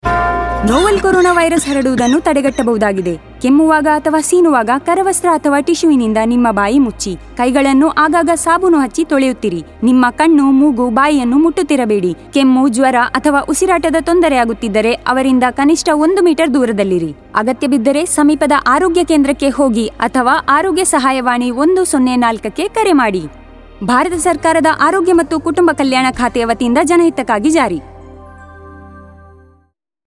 Radio PSA